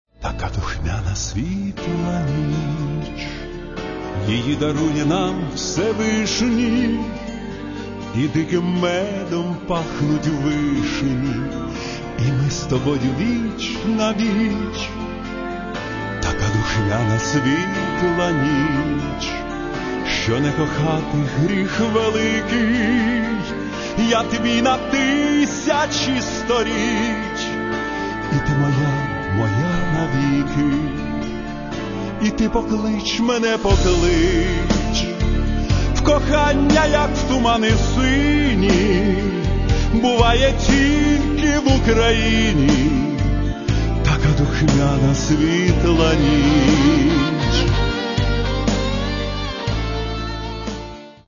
Каталог -> Естрада -> Поети та композитори